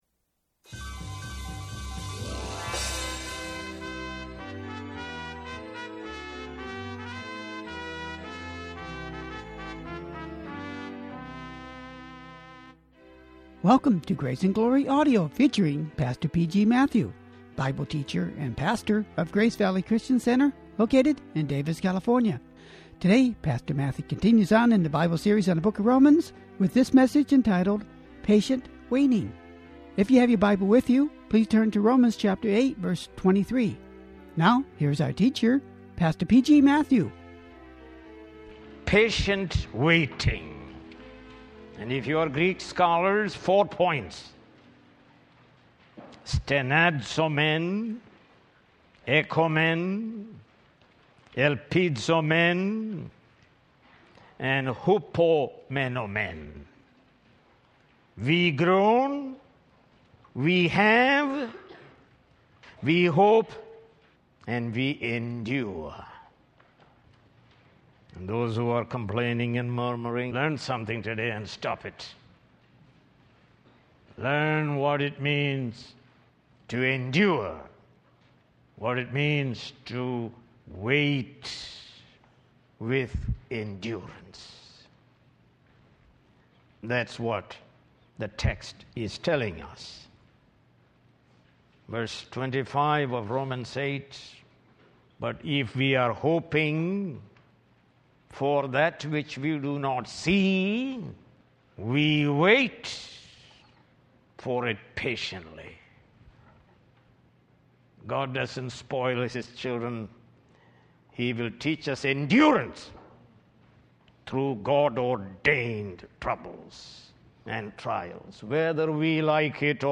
More Sermons From the book of Romans